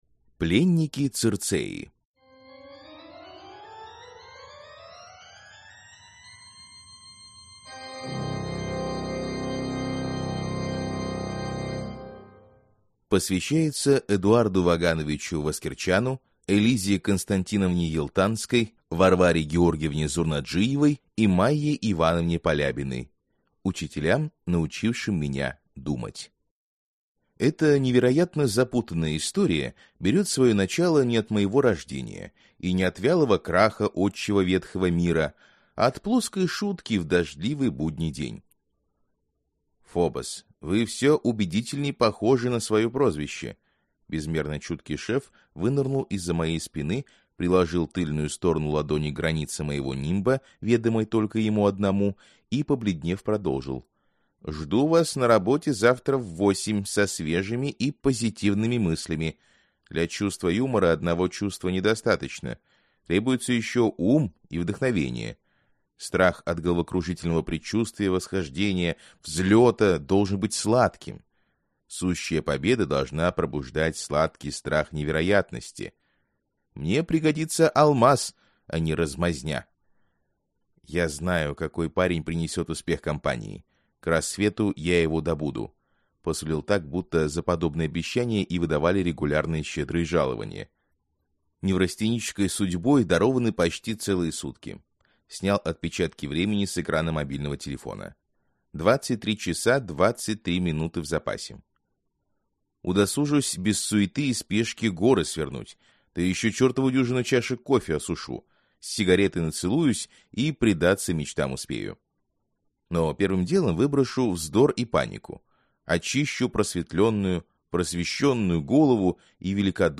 Аудиокнига Пленники Цирцеи | Библиотека аудиокниг